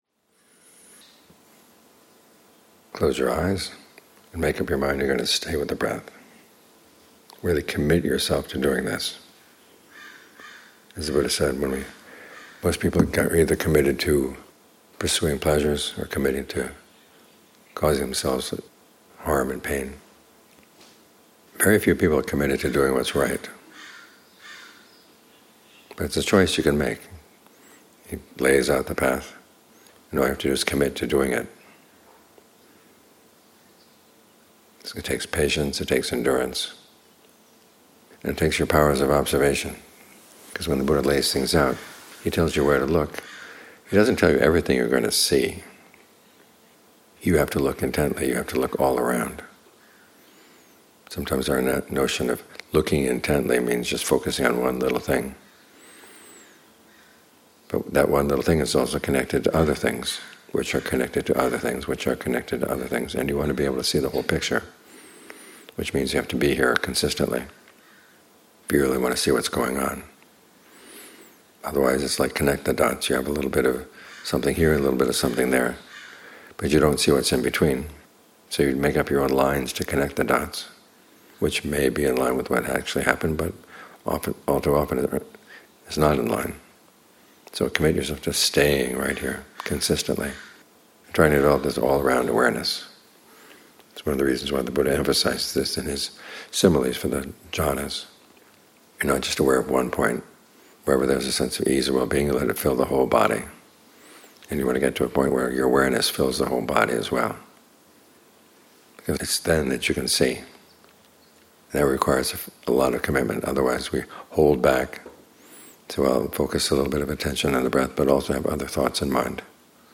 This talk hasn't been transcribed yet.